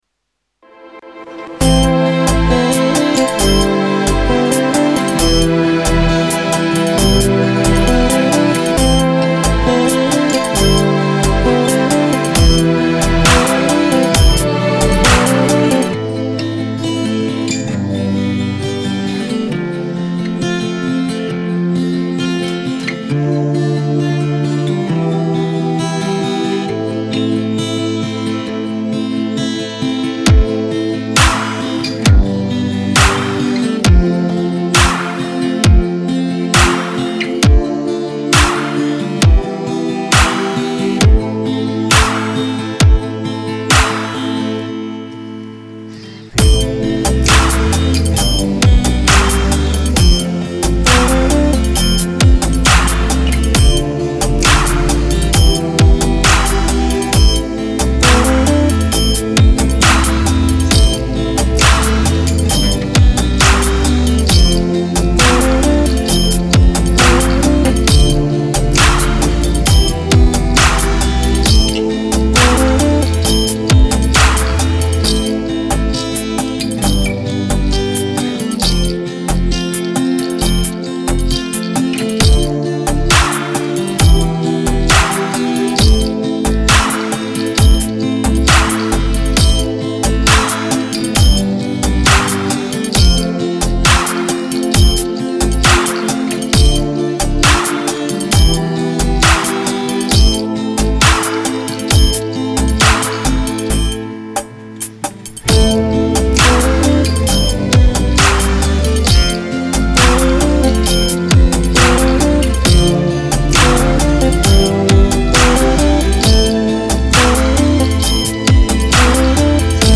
guitar
pop
chill
meditation
romantic
strings
sologuitar
film music